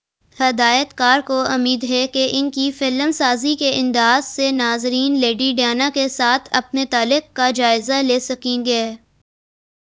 deepfake_detection_dataset_urdu / Spoofed_TTS /Speaker_01 /274.wav